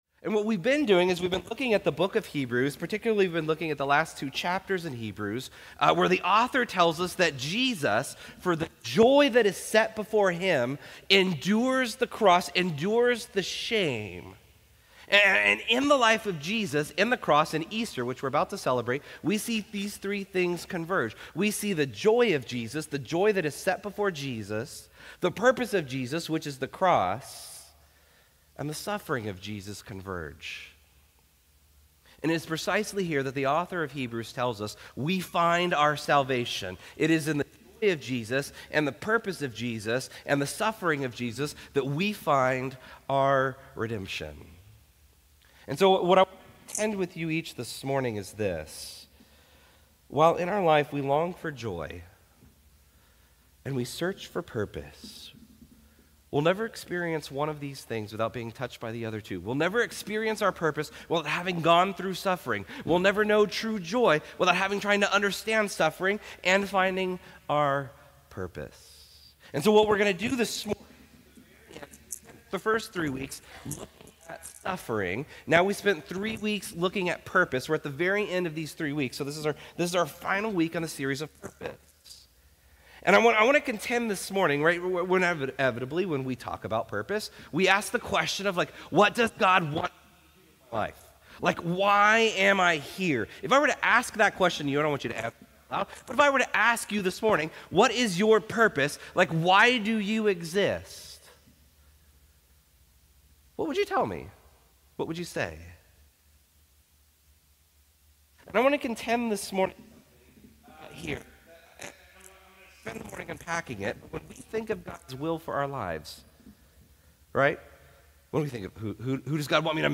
Restore Houston Church Sermons How to Know God’s Will Mar 27 2025 | 00:43:42 1x 00:00 / 00:43:42 Subscribe Share Apple Podcasts Overcast RSS Feed Share Link Embed